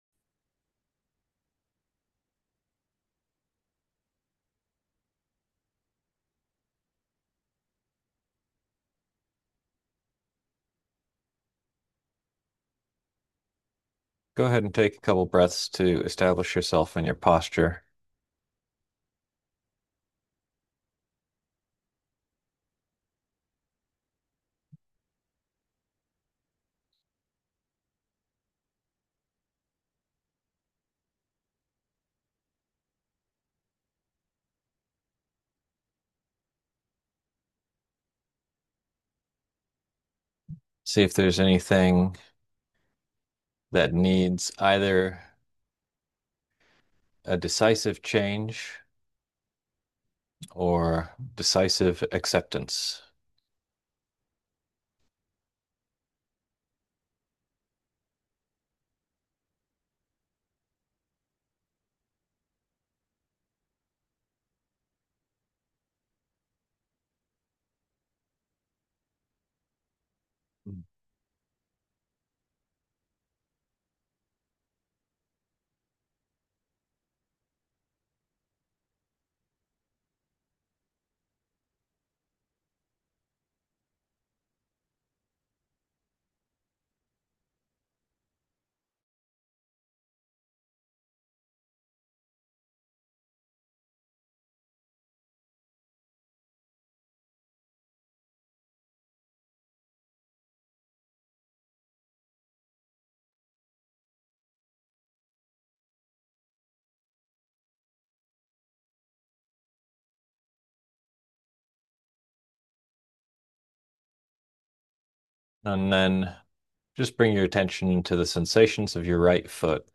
Ring in the New Year with your old, decrepit body. No, this meditation is not as depressing as it sounds.